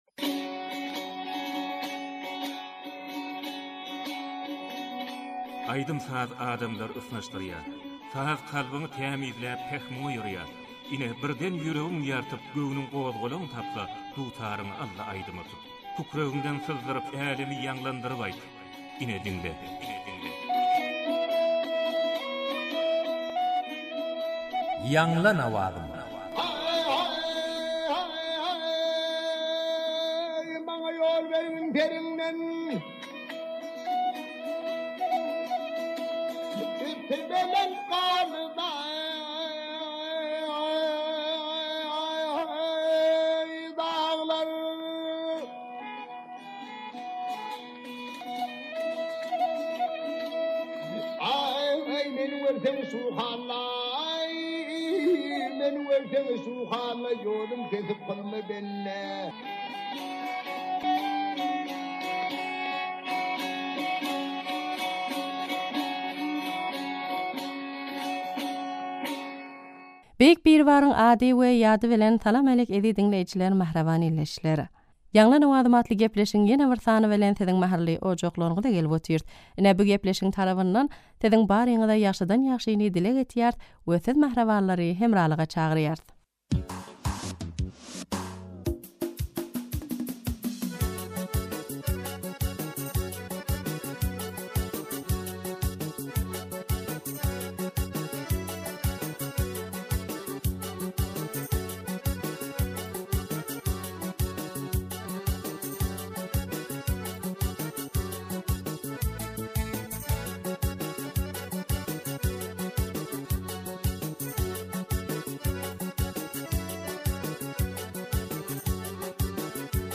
aýdym